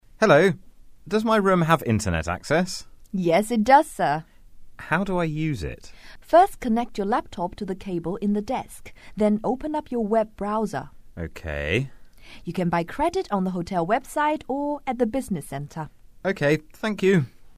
英语初学者口语对话第22集：我的房间能上网吗？